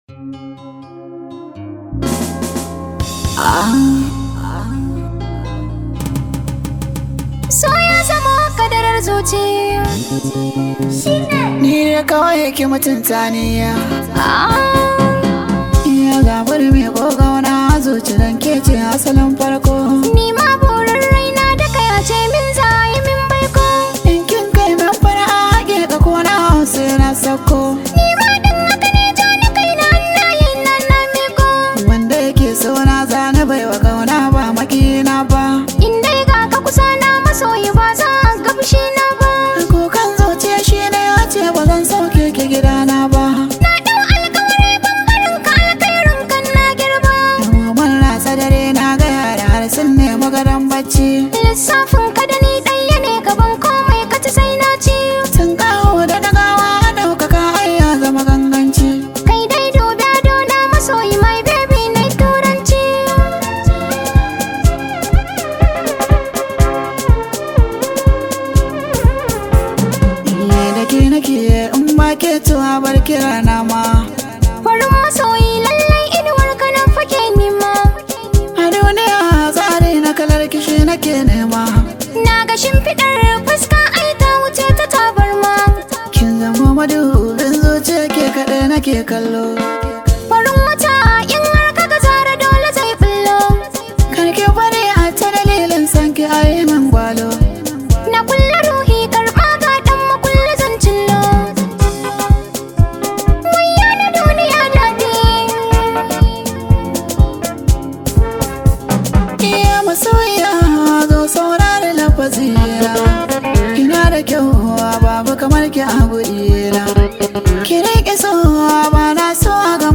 Hausa Musics